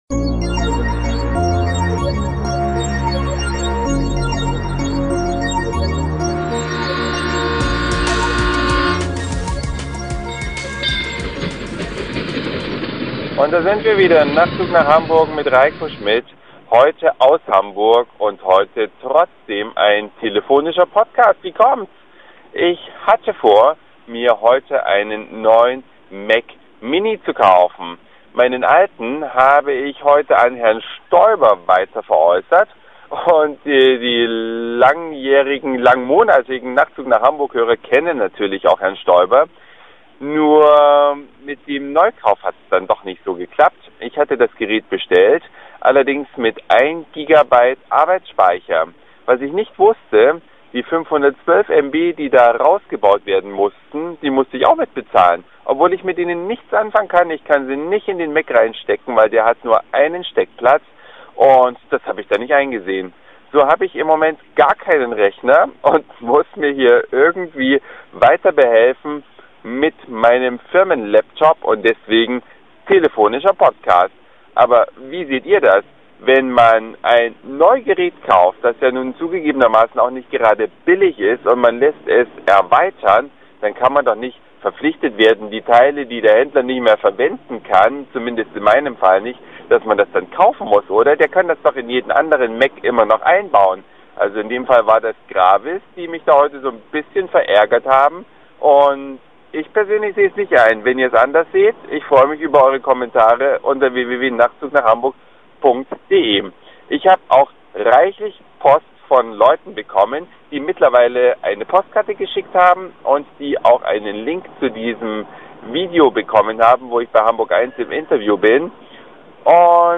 Obwohl ich heute aus Hamburg sende, gibt es einen telefonischen